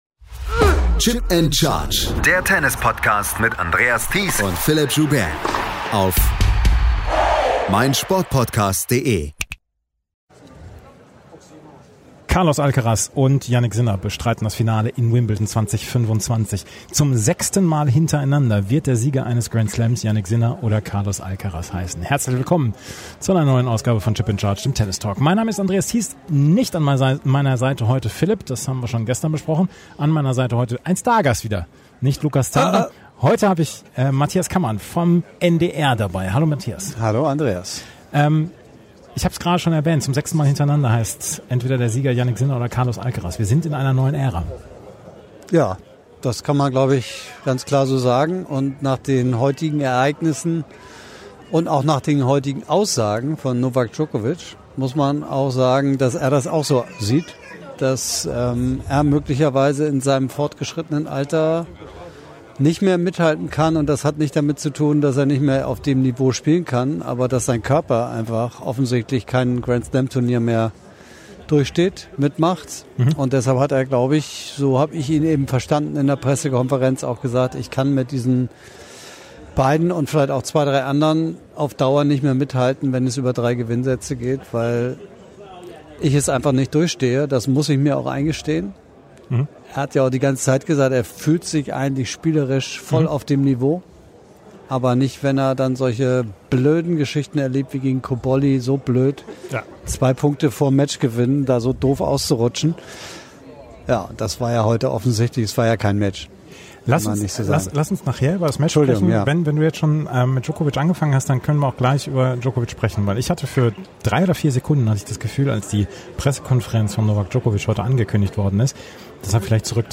Gemeinsam sprechen die beiden über die Nachrichten des Tages.